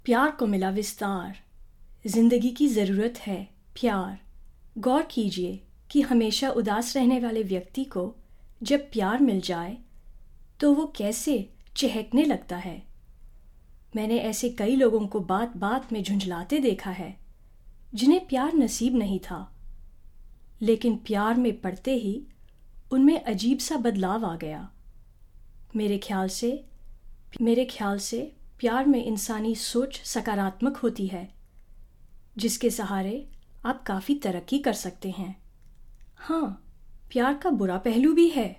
Educated, intelligent voice ideal for business or legal commentary, technical speaking, scientific jargon and literature as well as philosophy.
Sprechprobe: eLearning (Muttersprache):